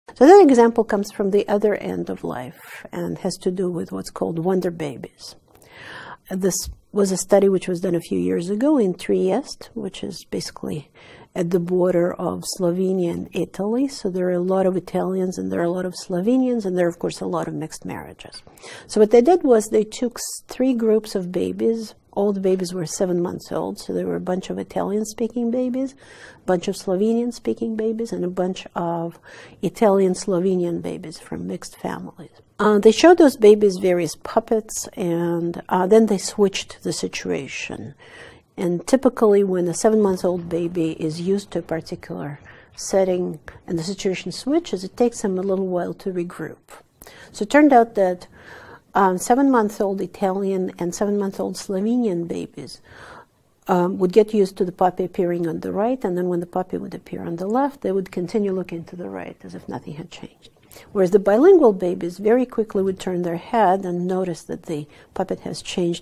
The lecture is about a study in order to indicate the cognitive advantages of bilingualism. The researchers took three groups of seven-month-old babies, including monolingual babies and bilingual babies.